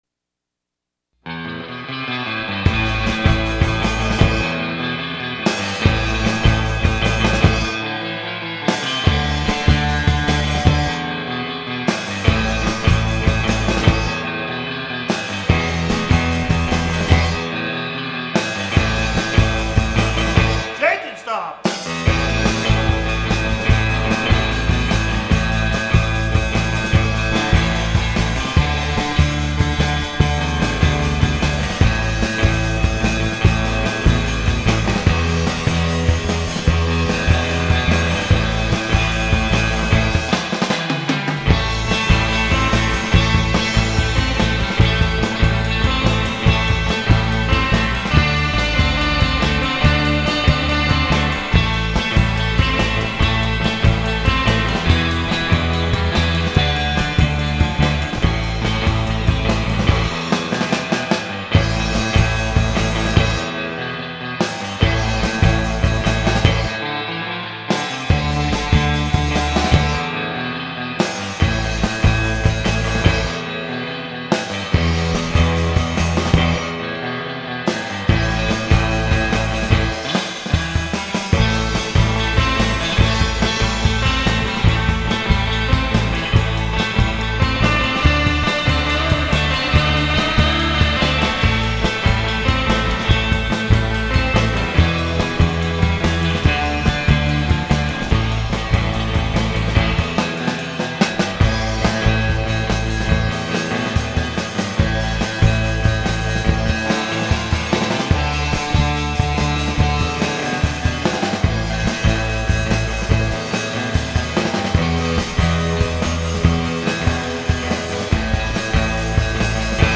Rock
Instrumental Surf Rock